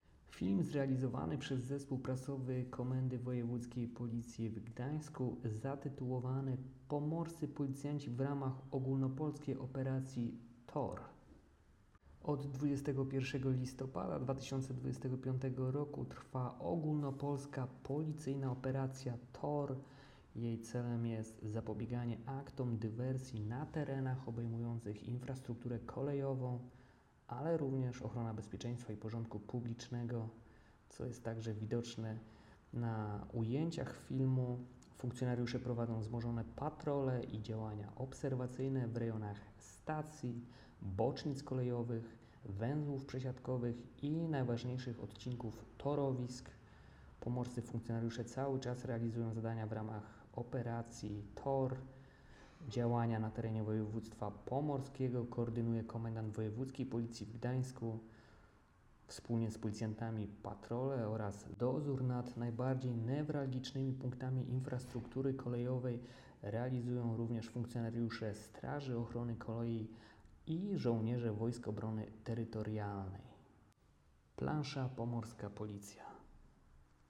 Nagranie audio audiodyskrypcja__5_.m4a